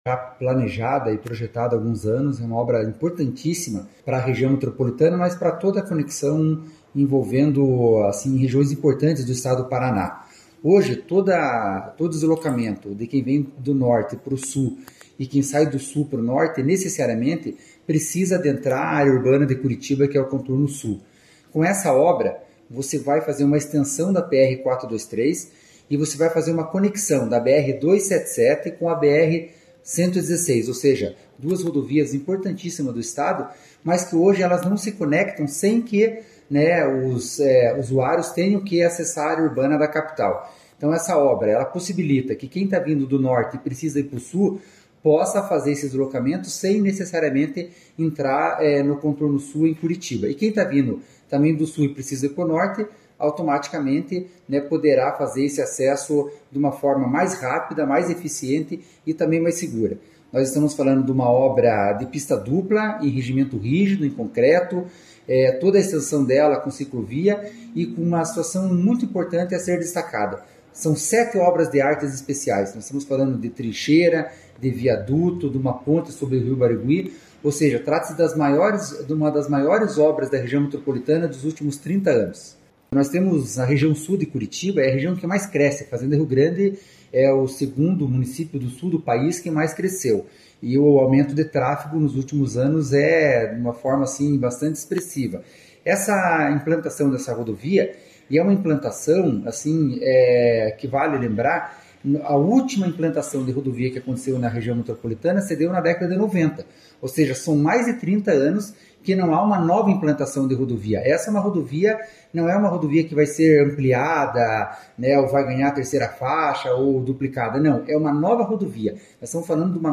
Sonora do presidente da Amep, Gilson Santos, sobre o corredor metropolitano de Curitiba